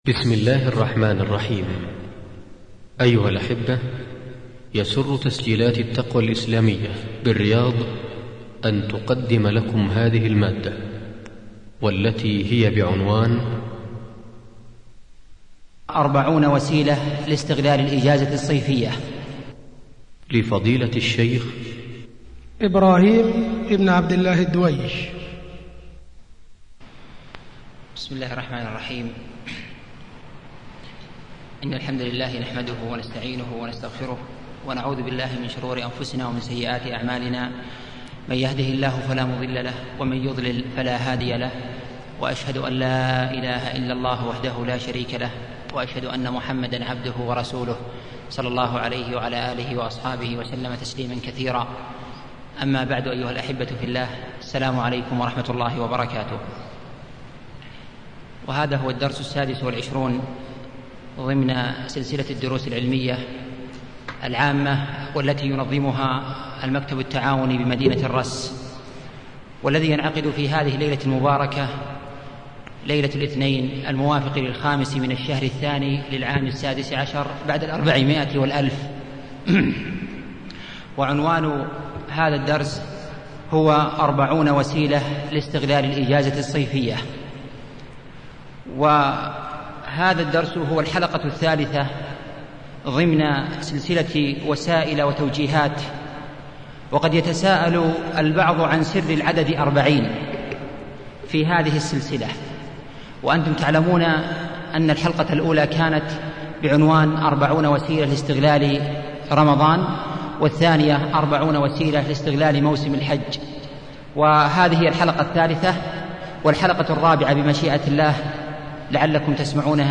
محاضراة